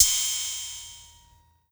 Crashes & Cymbals
ILLMD028_CRASH_SOB.wav